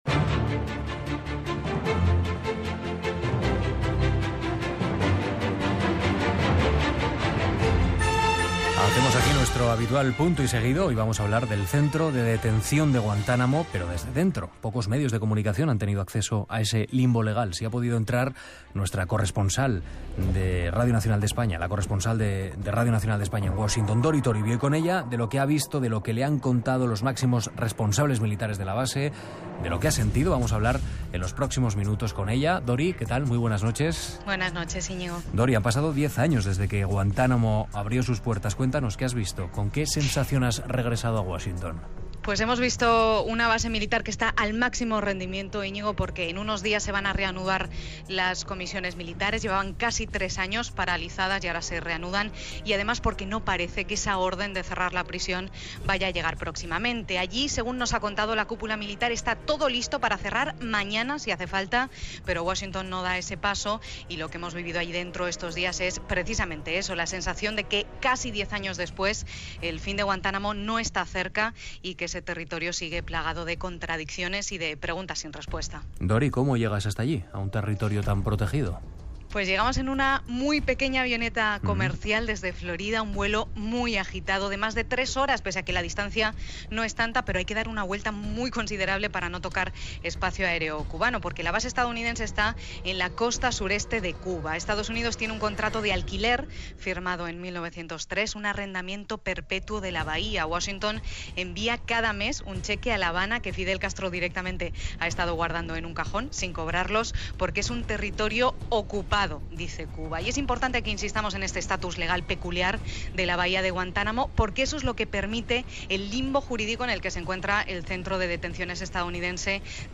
Reportatge fet sobre el centre de detencions de Guantánamo dels EE.UU., que té a l'illa de Cuba Gènere radiofònic Informatiu